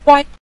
guai5.mp3